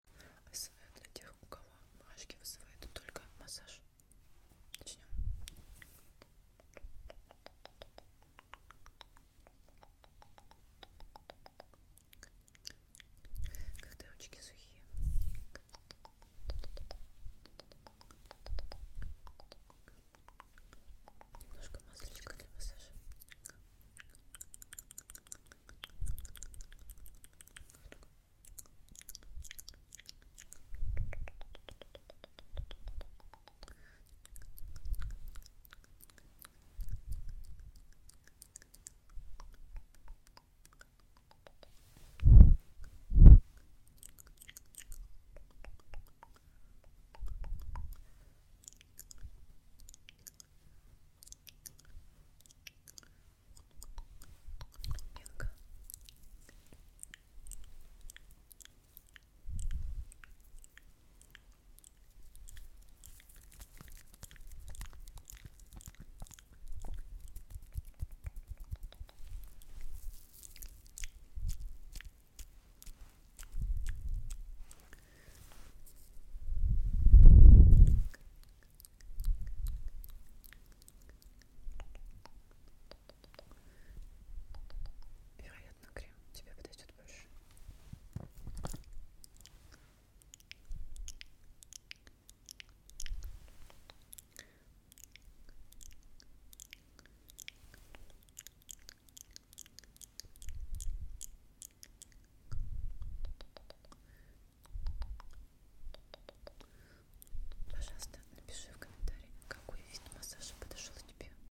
АСМР и четыре вида массажа sound effects free download
АСМР и четыре вида массажа помогут вам расслабиться.